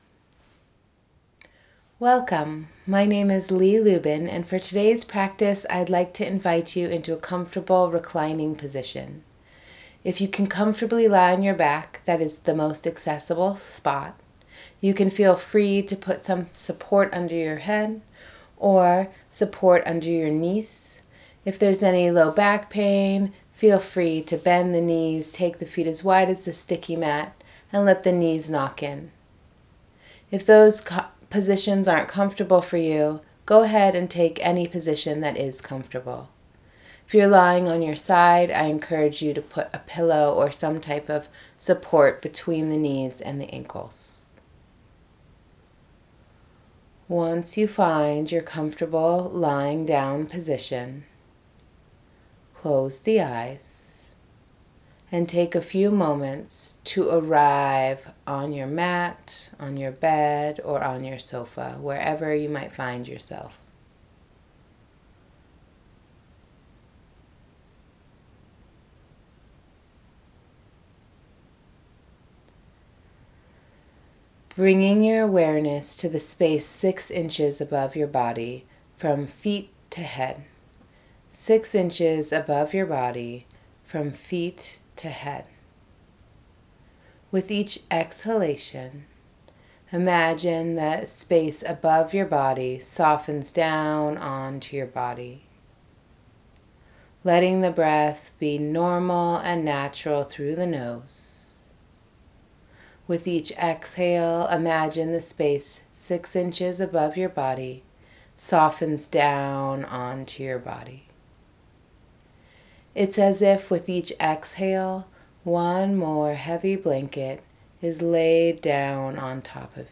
Guided Relaxation